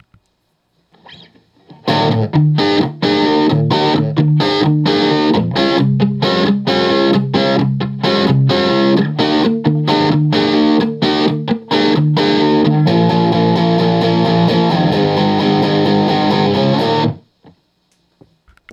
All recordings in this section were recorded with an Olympus LS-10. Distorted amp sounds are an Axe-FX Ultra set to “Basic Brit 800” with no effects.
1997 S-100 Bridge Pickup
Oh, and apologies for the guitars being slightly out of tune with each other.
The first sound bytes are just some A-shape barre chord riffs starting on the fifth fret with some sus-4 embellishment thrown in for good measure. This was recorded using the bridge pickup with all guitar controls on 10.